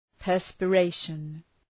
Προφορά
{,pɜ:rspə’reıʃən}